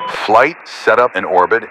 Radio-playerWingmanOrbit2.ogg